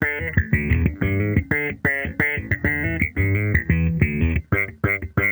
Index of /musicradar/sampled-funk-soul-samples/90bpm/Bass
SSF_JBassProc2_90E.wav